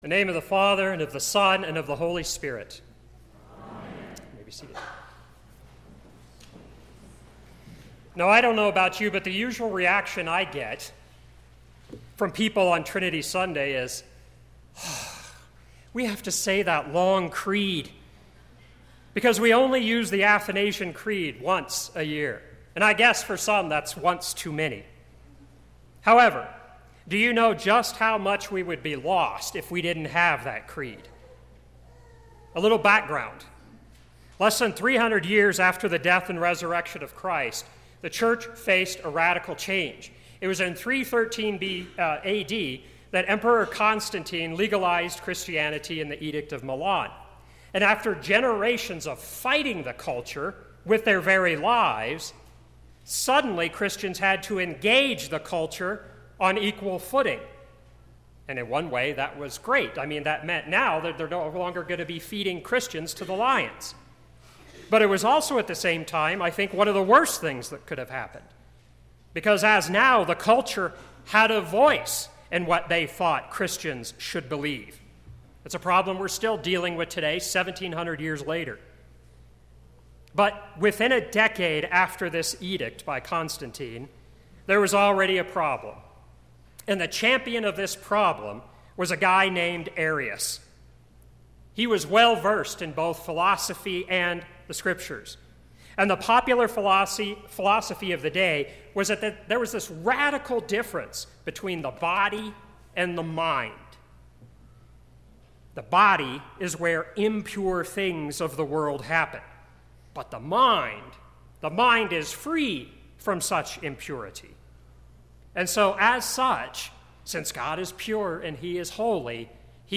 Sermon - 5/27/2018 - Wheat Ridge Lutheran Church, Wheat Ridge, Colorado